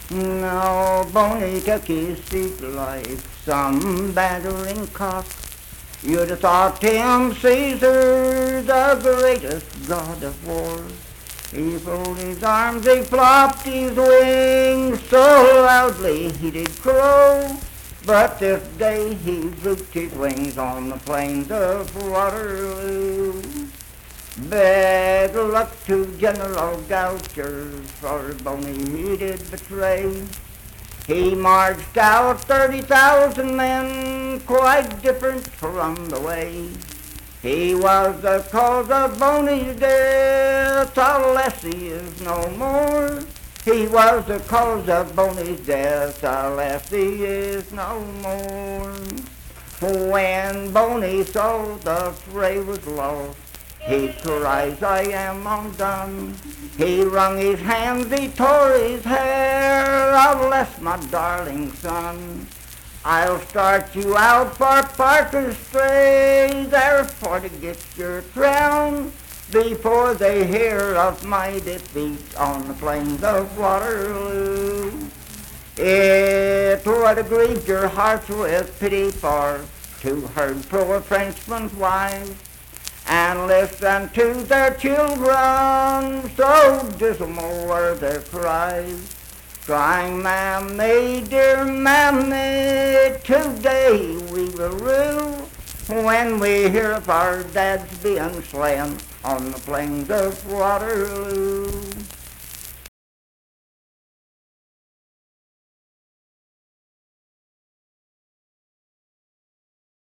Old Boney - West Virginia Folk Music | WVU Libraries
Unaccompanied vocal music performance
Verse-refrain 4d (4).
Voice (sung)